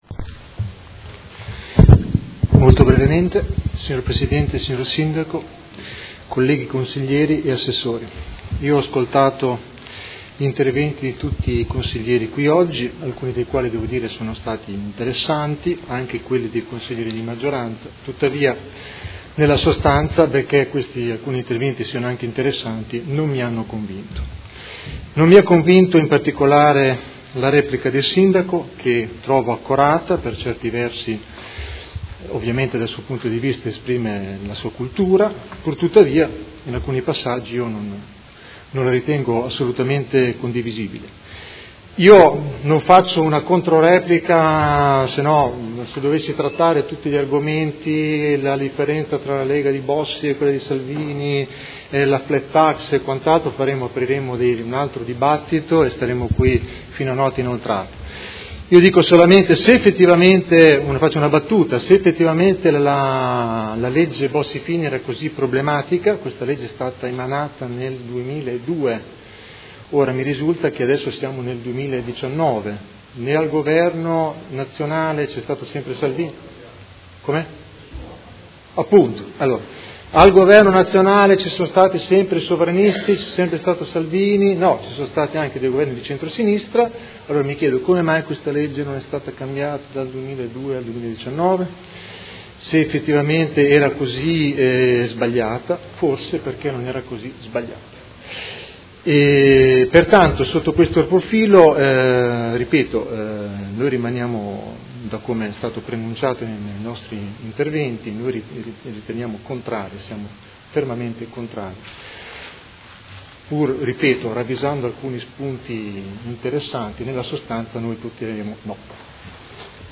Seduta del 20/06/2019. Dichiarazione di voto su proposta di deliberazione: Indirizzi Generali di Governo 2019-2024 - Discussione e votazione